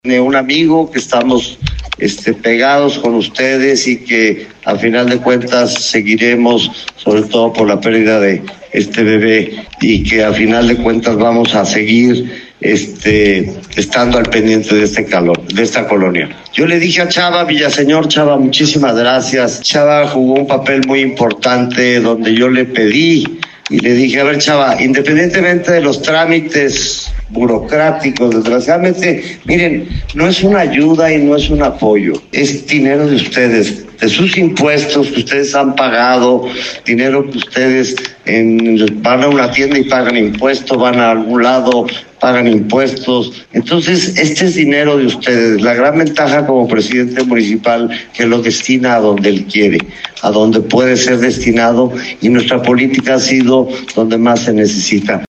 El ayuntamiento de Zapopan entregó apoyos económicos a los afectados por la lluvia del pasado 15 de julio en las colonias Lomas de Tabachines y la Martinica, en total fueron 144 fincas afectadas entre las cuales se contabilizaron 31 negocios cada familia recibirá un cheque por hasta 45,000 y en algunos casos se les otorgará menaje habla el presidente Juan José frangie